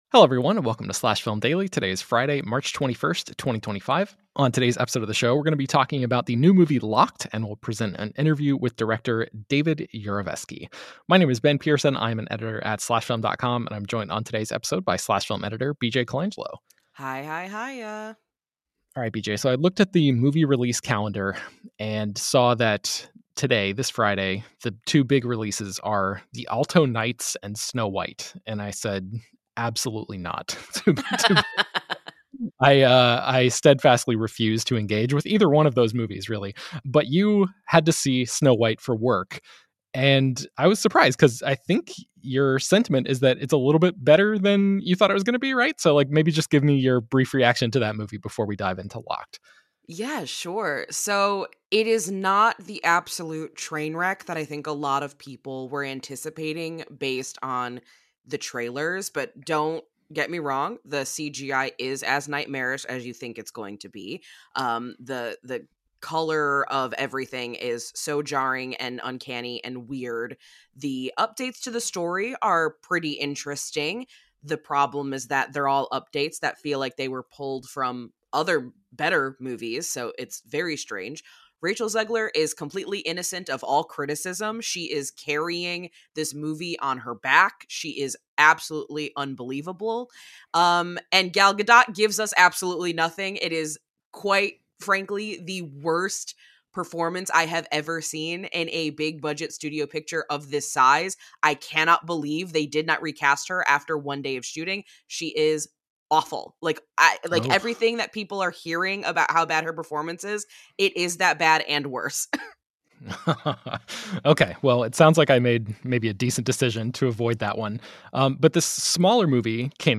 Entertainment News, News, Film Reviews, Tv Reviews, Tv & Film